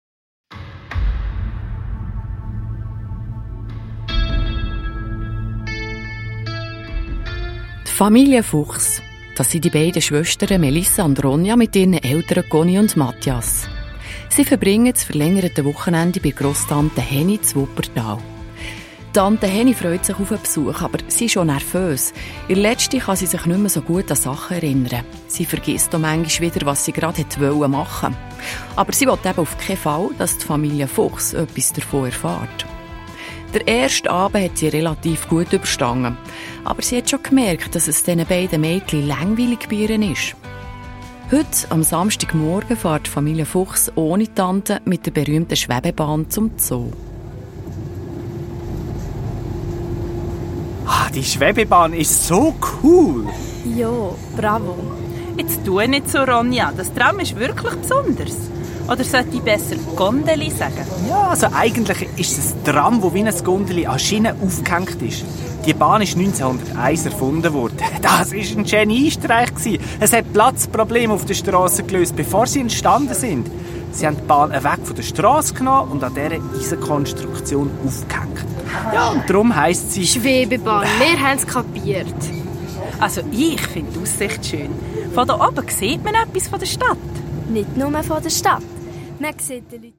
Hörspiel-CD mit Download-Code